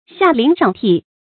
下陵上替 注音： ㄒㄧㄚˋ ㄌㄧㄥˊ ㄕㄤˋ ㄊㄧˋ 讀音讀法： 意思解釋： 在下者凌駕于上，在上者廢弛無所作為。